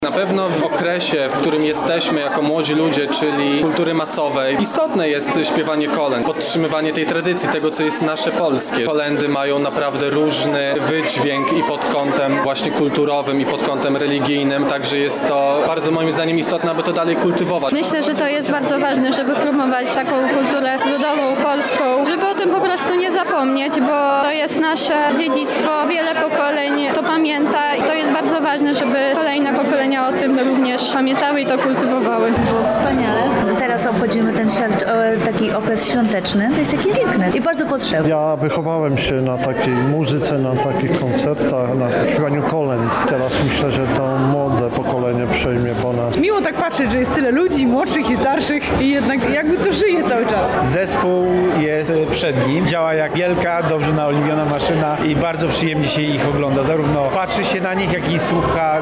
W Chatce Żaka odbył się Koncert Kolęd.
O wrażeniach i ważności wydarzeń promujących kulturę ludową mówią widzowie oraz uczestnicy ZTL:
Relacja